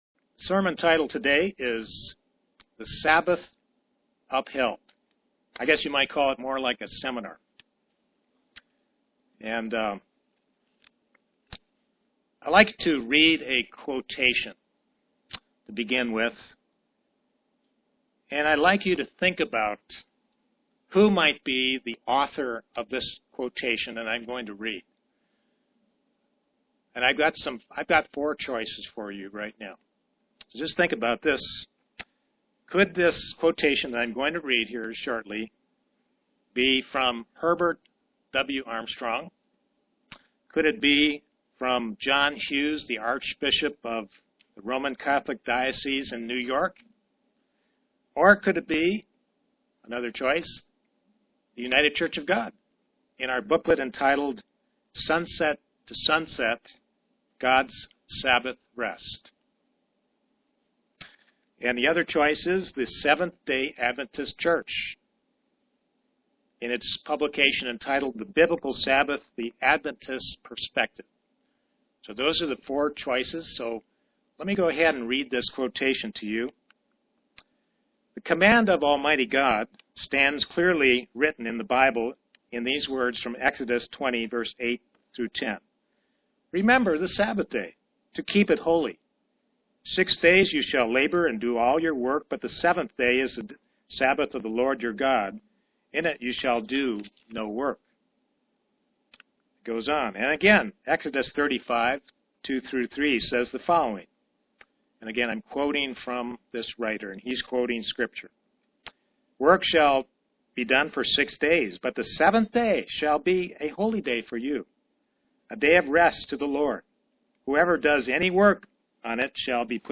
Given in Knoxville, TN
Print Proof that God continues with the Sabbath Day to Christians UCG Sermon Studying the bible?